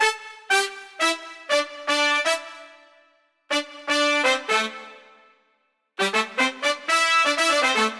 31 Brass PT2.wav